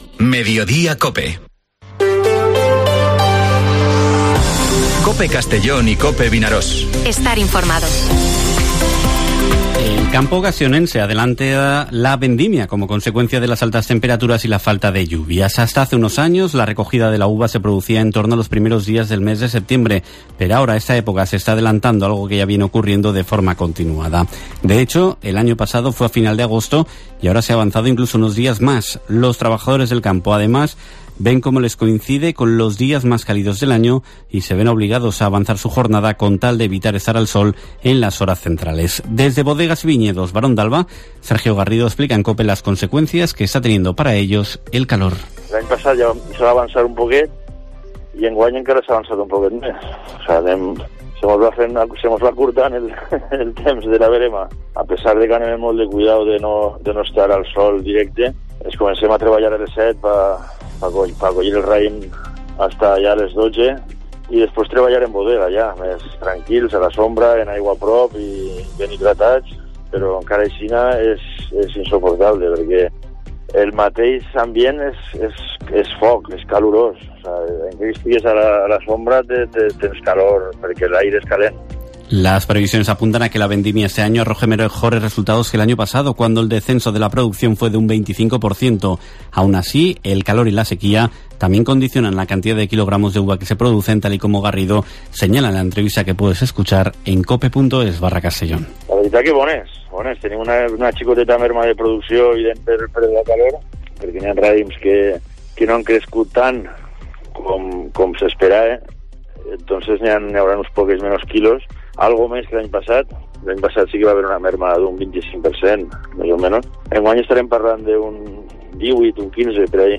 Informativo Mediodía COPE en la provincia de Castellón (23/08/2023)